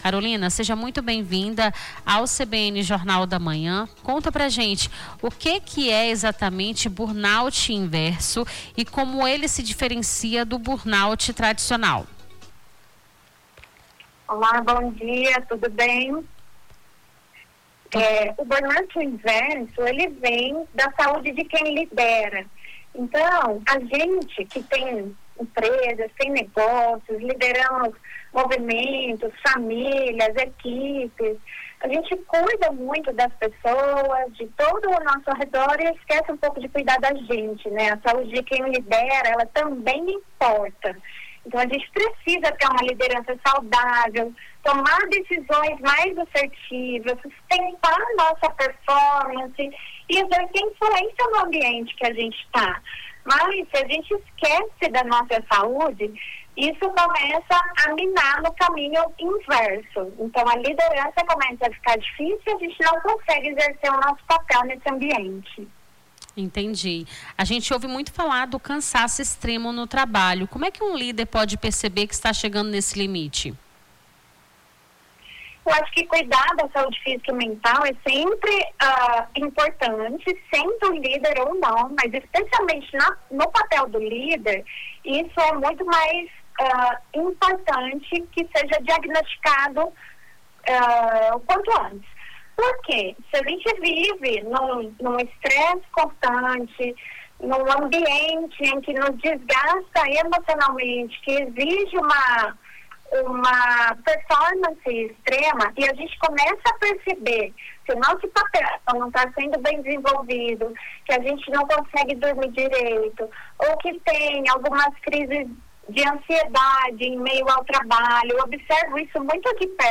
Nome do Artista - CENSURA - ENTREVISTA BURNOUT INVERSO 20-04-26.mp3